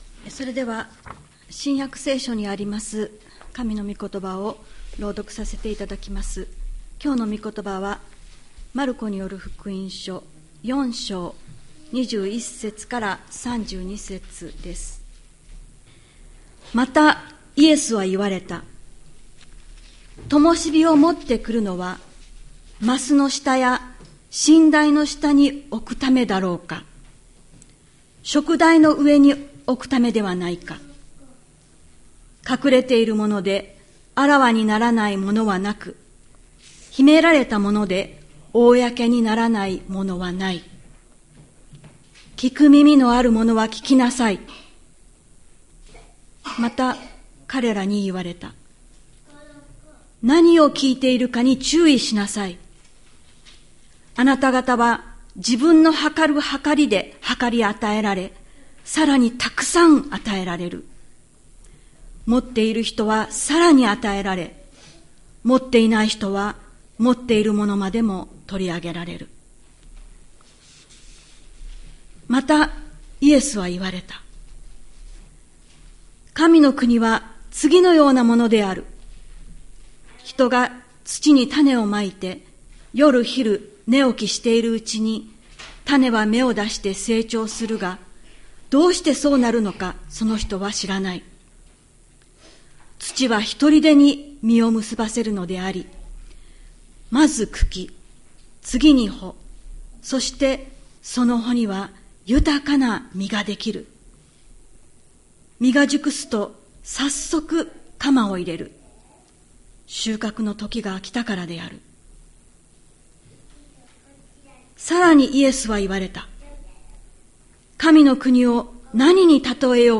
千里山教会 2024年11月10日の礼拝メッセージ。